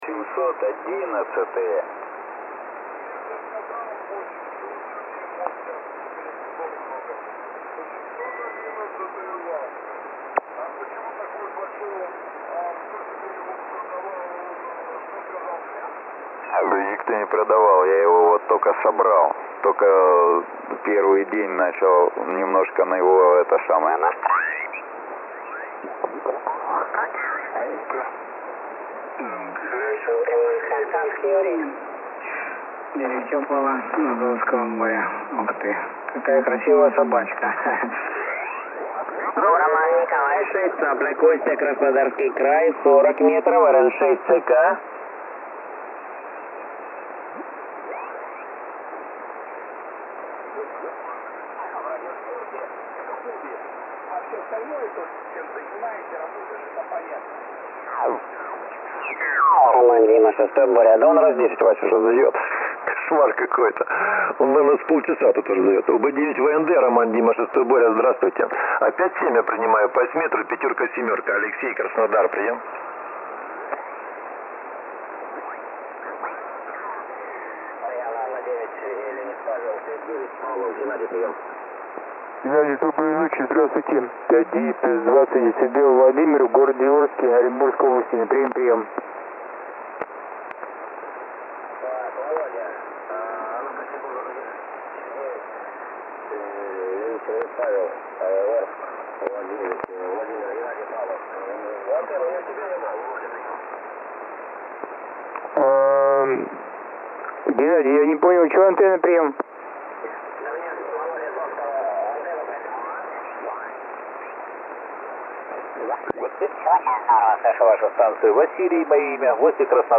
Запись производил на диапазоне 40 м, PRE отключен, RF +2. Сначала запись SSB сигнала.